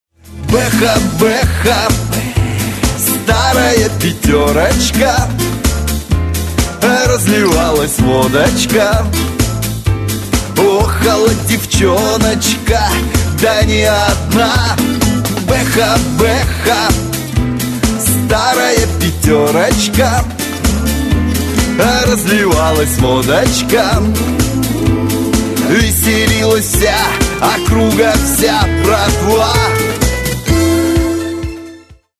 Категория : Шансон (реалтоны)